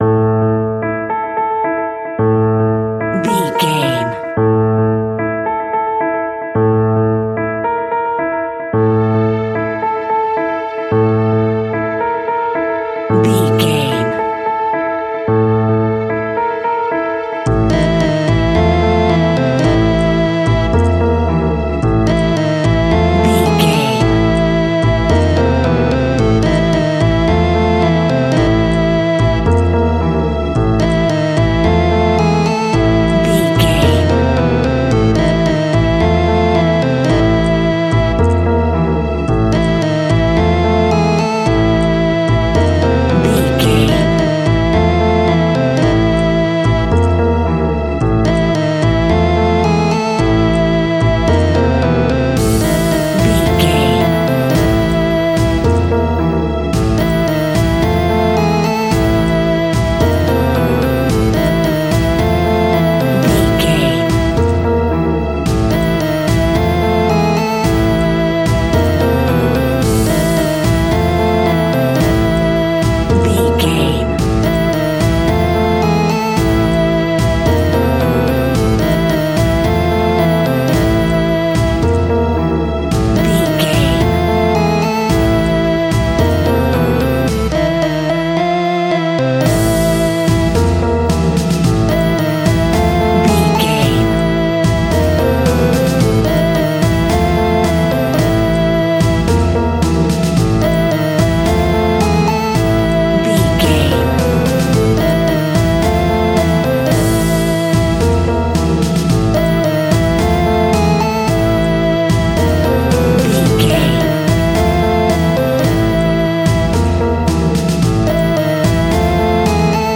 In-crescendo
Thriller
Aeolian/Minor
tension
ominous
dark
eerie
Horror Synths
horror piano
Scary Strings